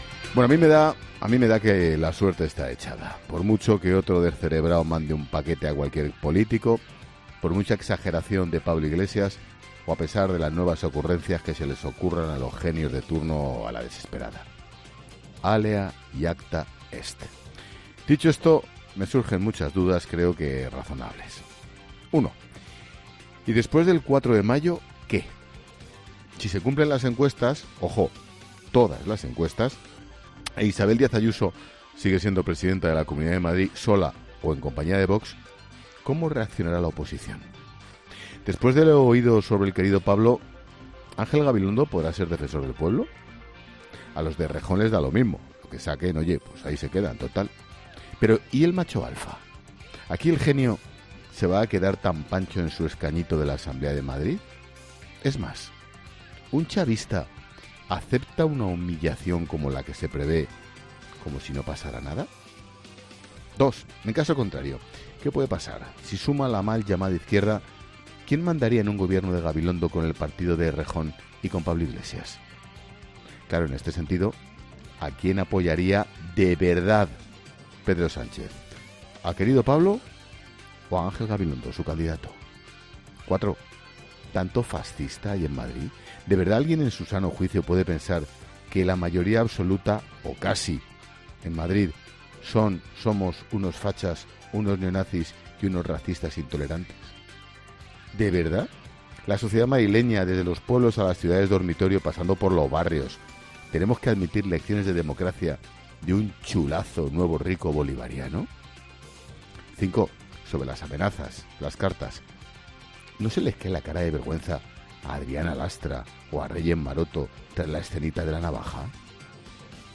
Monólogo de Expósito
El director de 'La Linterna', Ángel Expósito, analiza en su monólogo la situación política actual previa al 4M